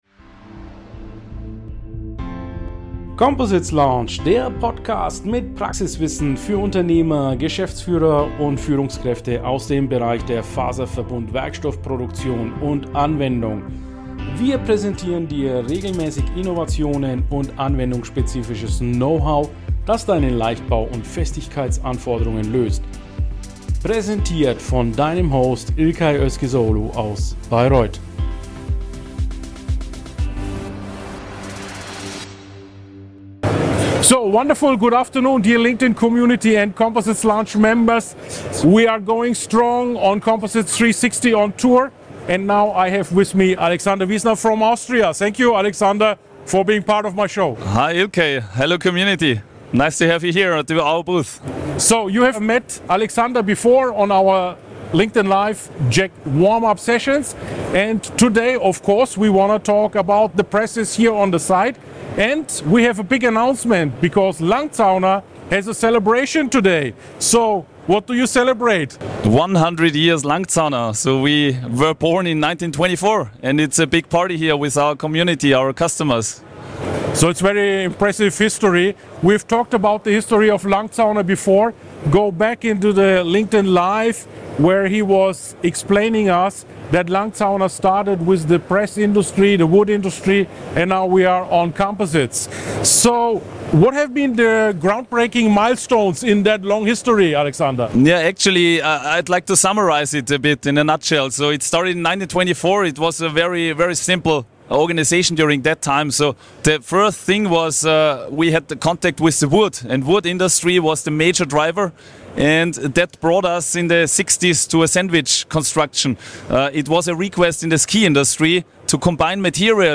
It was also a great opportunity to talk with the project management team.